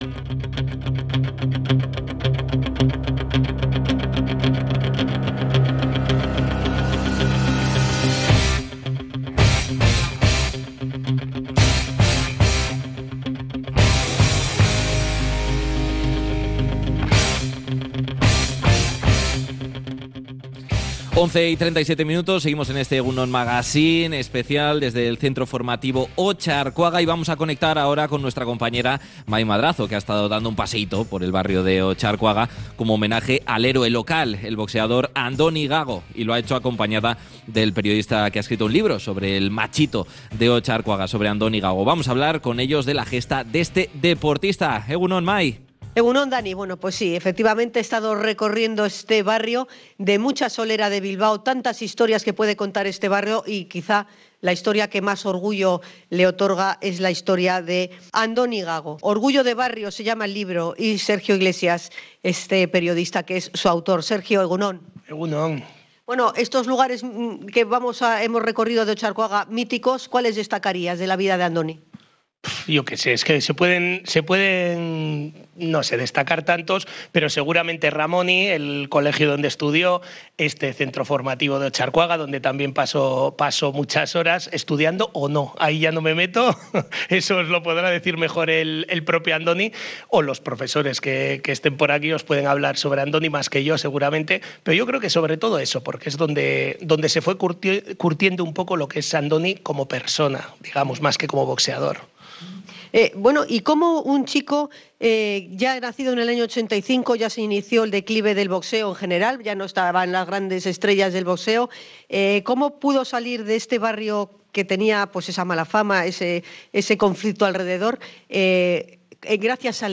INT.-ORGULLO-DE-BARRIO.mp3